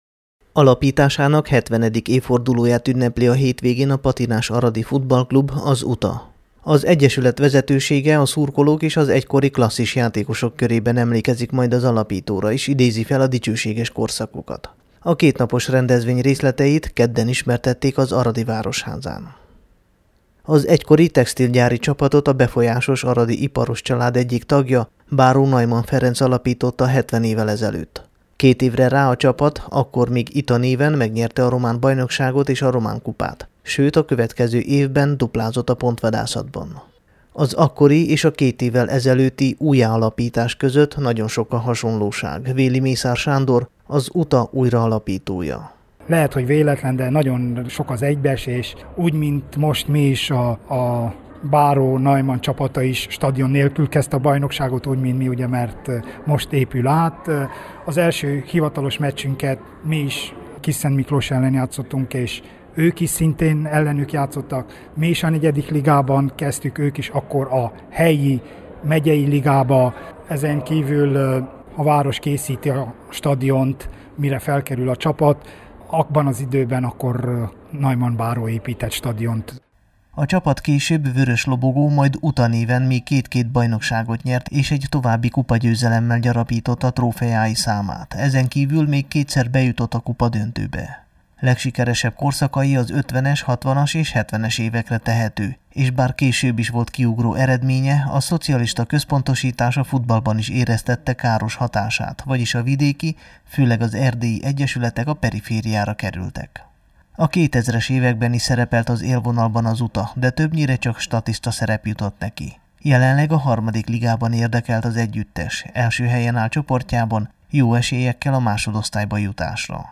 amely a Temesvári Rádió számára készült